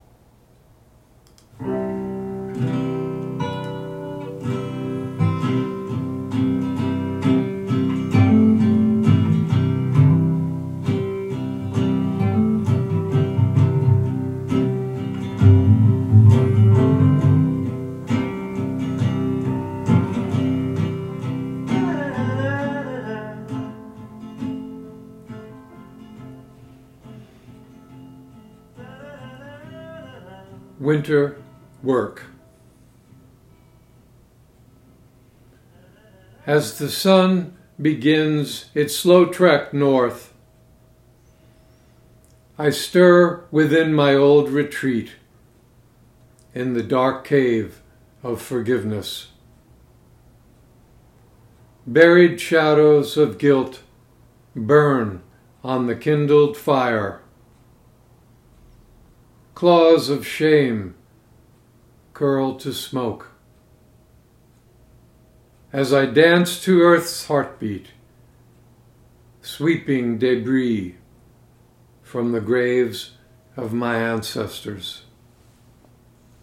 Reading of “Winter Work” with music by Bob Dylan.